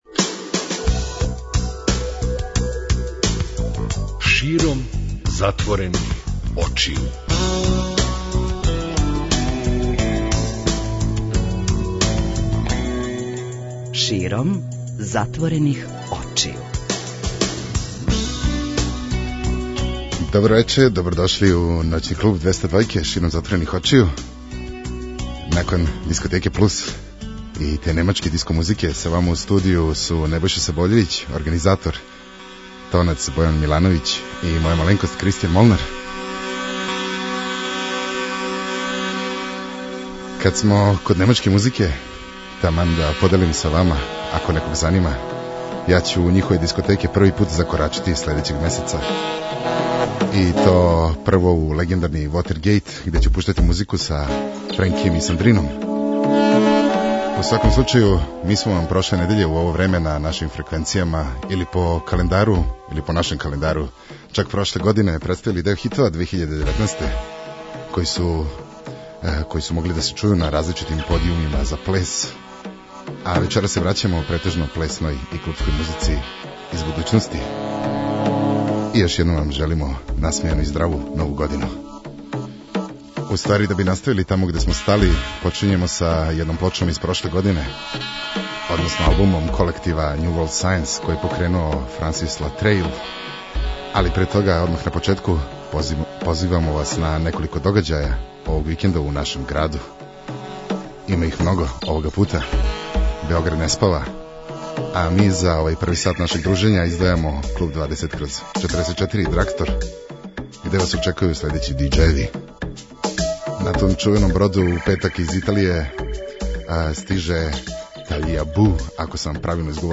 Ди-џеј